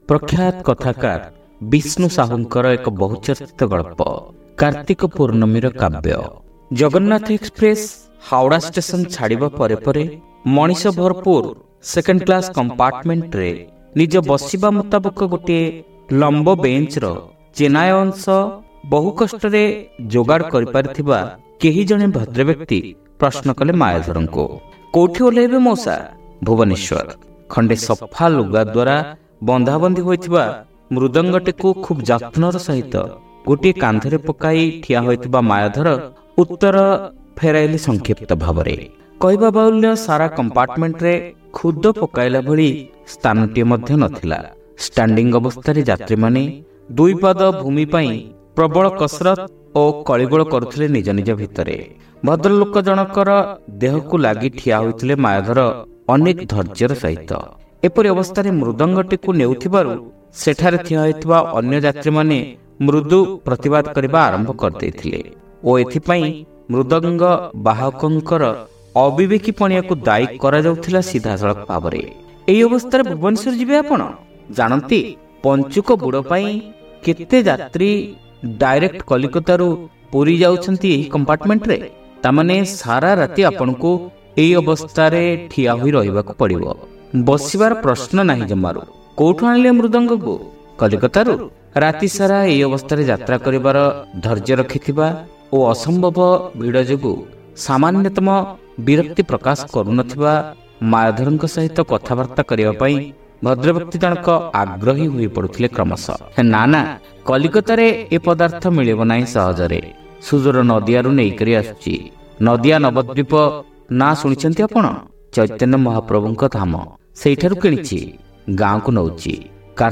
ଶ୍ରାବ୍ୟ ଗଳ୍ପ : କାର୍ତ୍ତିକ ପୂର୍ଣ୍ଣମୀର କାବ୍ୟ